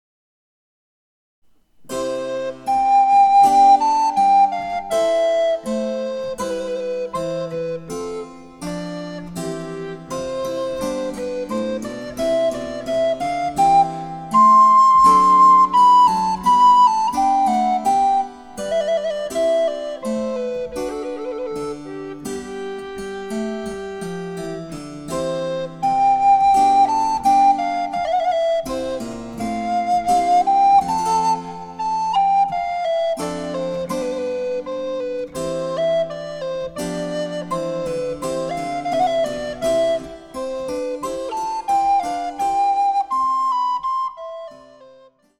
明るくのびやかな旋律、素朴な牧歌性と都会的な洗練との独特な混交の魅力はここでも健在です。
■リコーダーによる演奏
チェンバロ（スピネット）